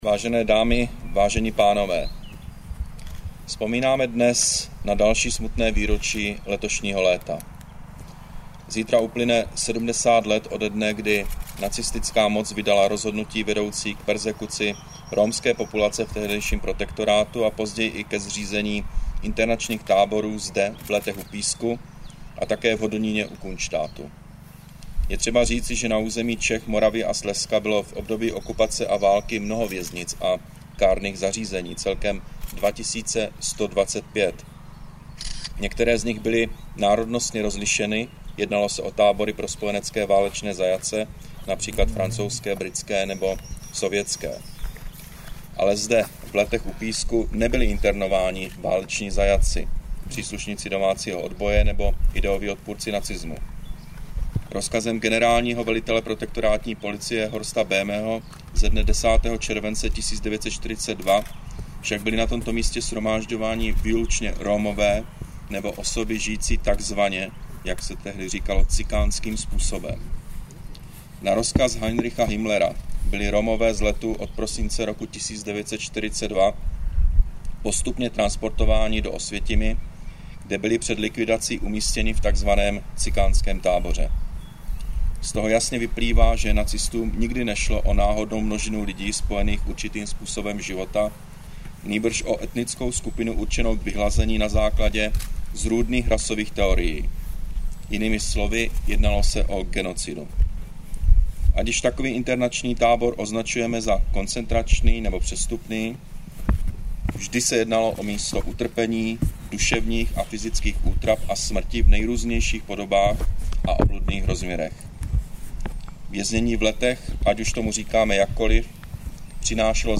Projev Premiéra Petra Nečase u příležitosti pietní vzpomínky v Letech u Písku.